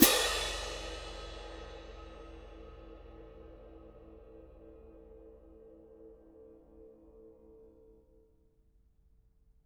cymbal-crash1_mf_rr2.wav